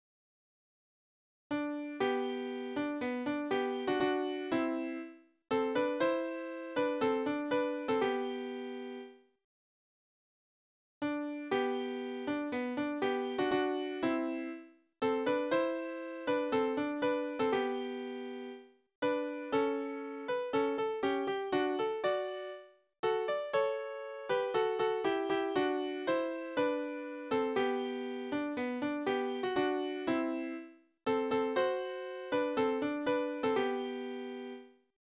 Liebeslieder: Die Liebe zur Schäferin
Tonart: G-Dur
Taktart: 4/4
Tonumfang: große Dezime
Besetzung: vokal